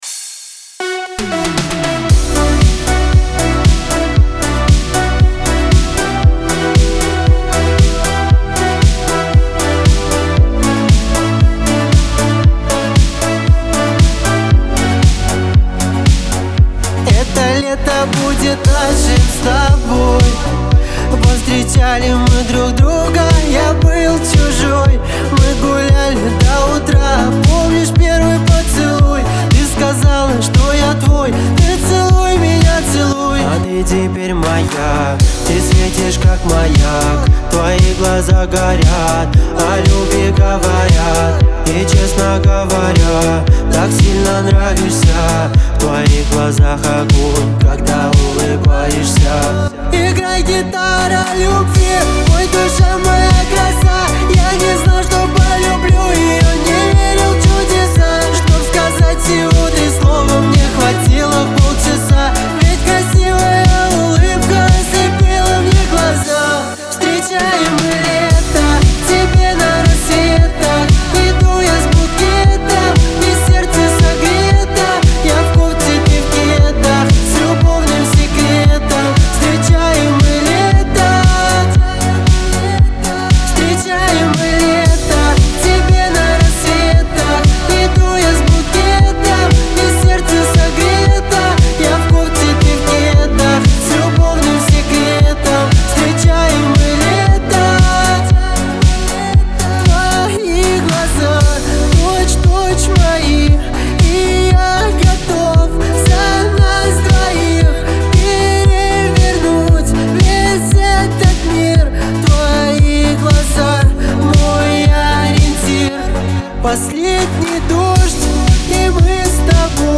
диско , Веселая музыка